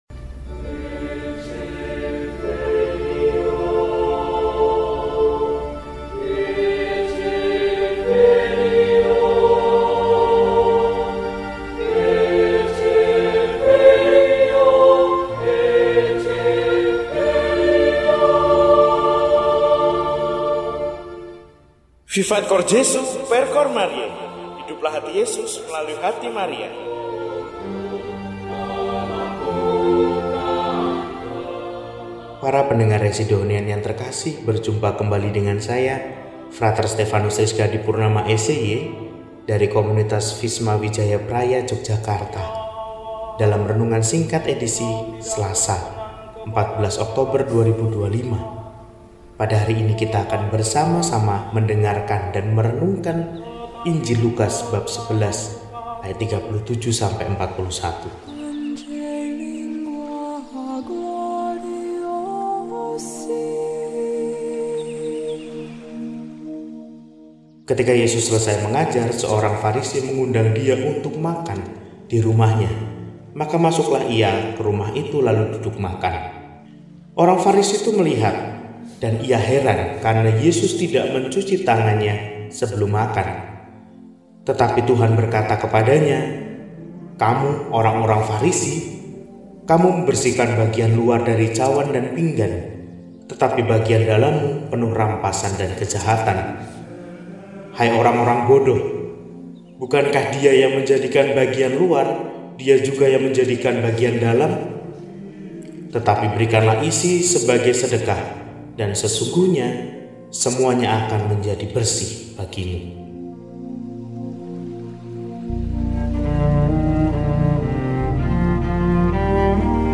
Selasa, 14 Oktober 2025 – Hari Biasa Pekan XXVIII – RESI (Renungan Singkat) DEHONIAN